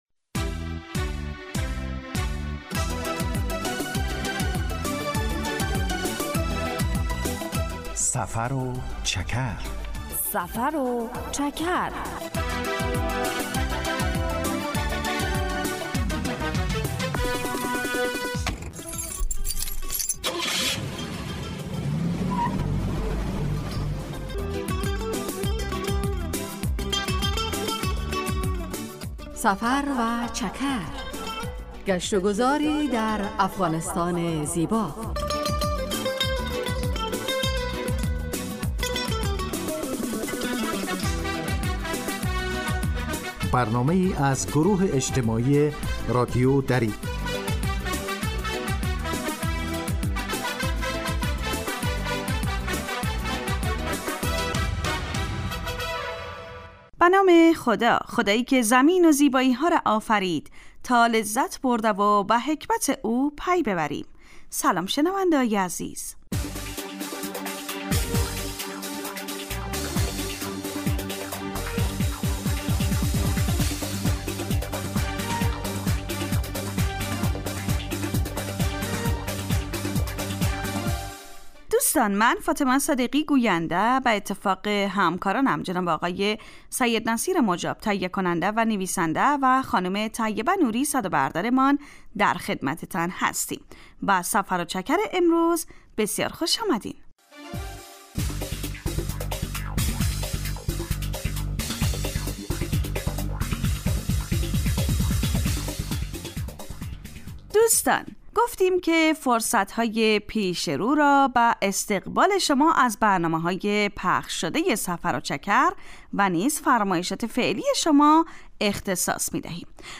در سفر و چکر ؛ علاوه بر معلومات مفید، گزارش و گفتگو های جالب و آهنگ های متناسب هم تقدیم می شود.